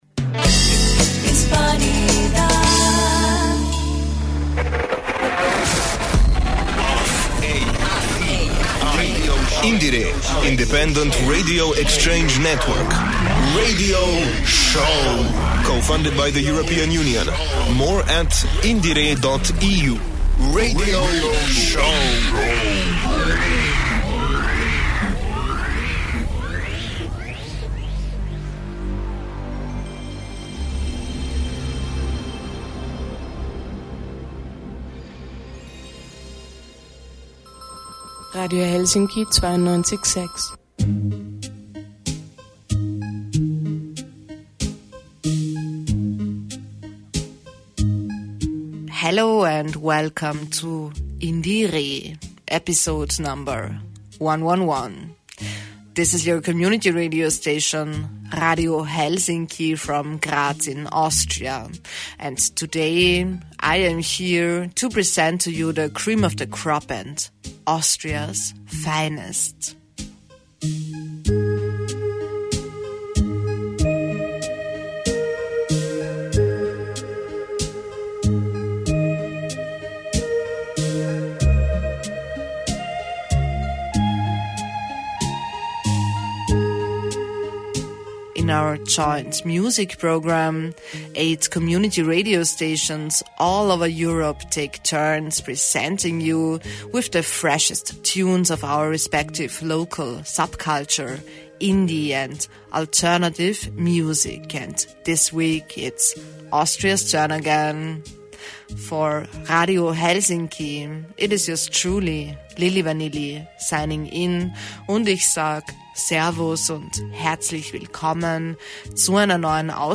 IndieRE #111 by Radio Helsinki, Graz, Austria In the 111th edition of our joint radio project, Radio Helsinki from Graz is taking you today to the heart of the underground, to SUb Graz, the coolest club in town, with up and coming punk bands Hyperdog and LEBER.